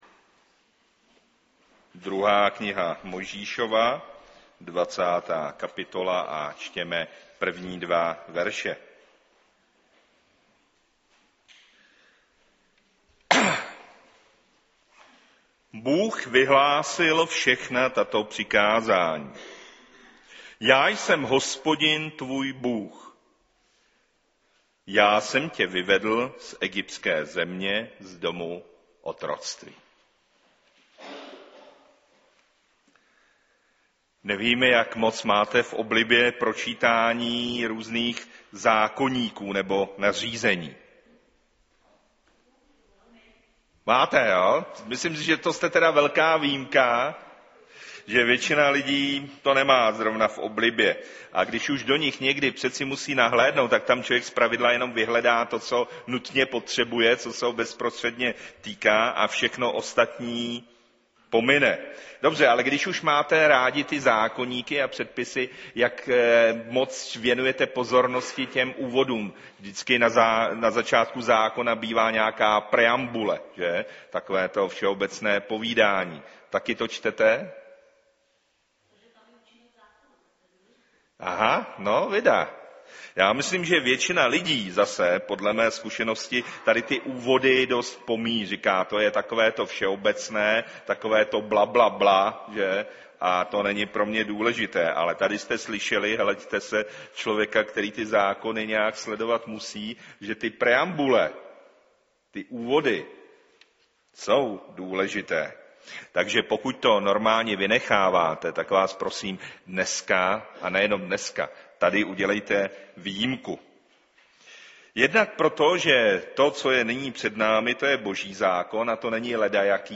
Kategorie: Nedělní bohoslužby Husinec